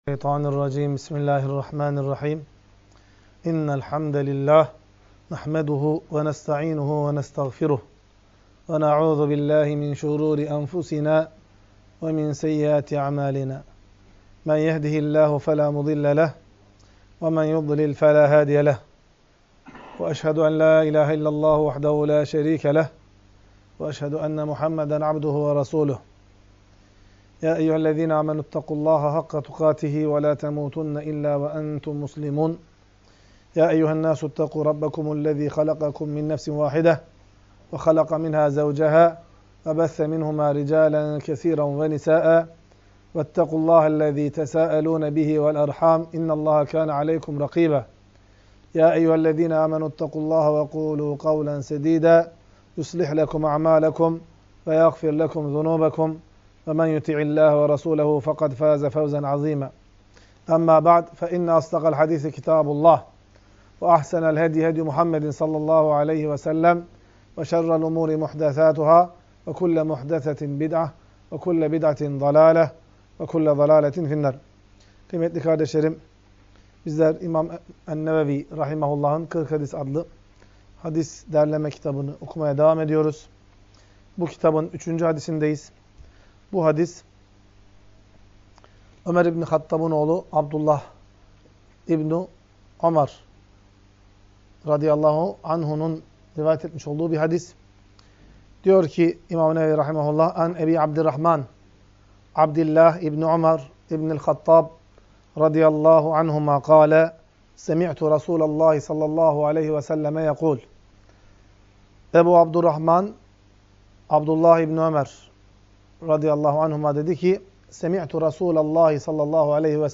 Ders - 3.